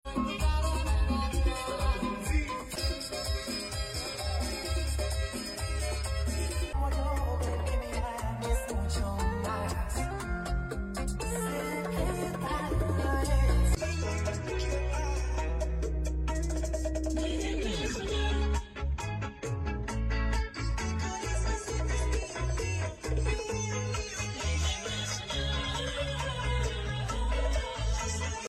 SQ wasn’t great with an old JBL 2 channel, but it was loud for the moment. That’s what the customer asked for!